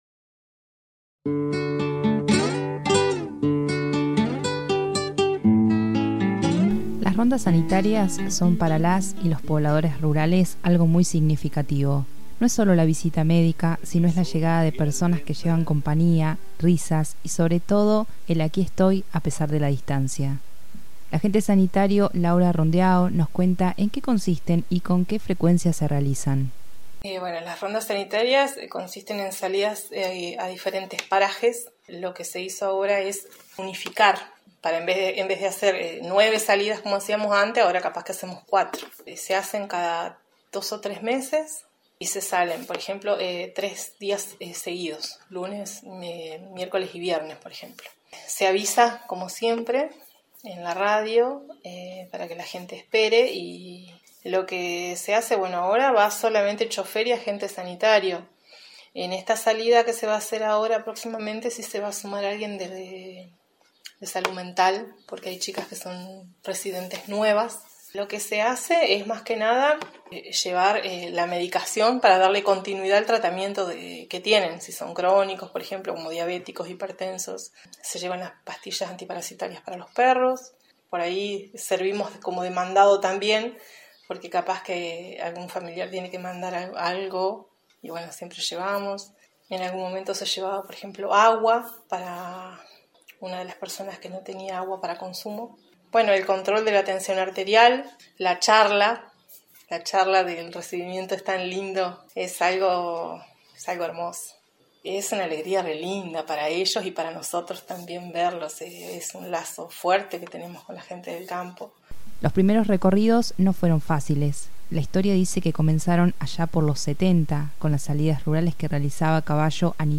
Escuchá la historia completa, en la voz de sus protagonistas.